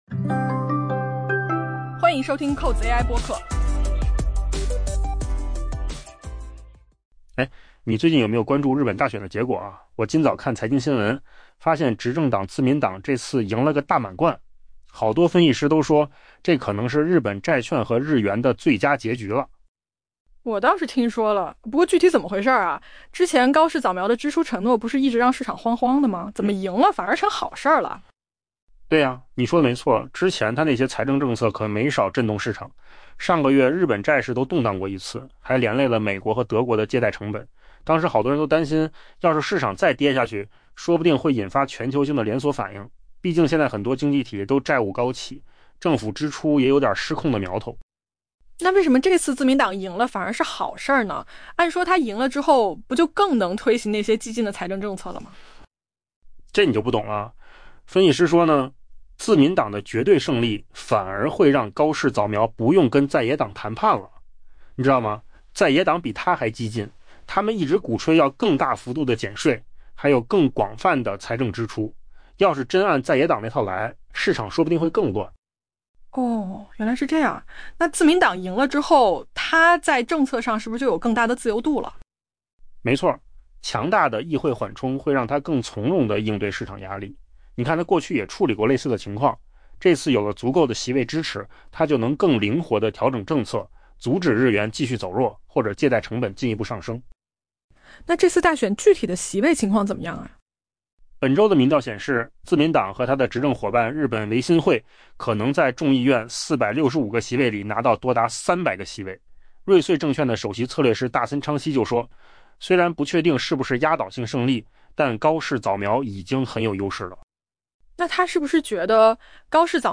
【文章来源：金十数据】AI播客：换个方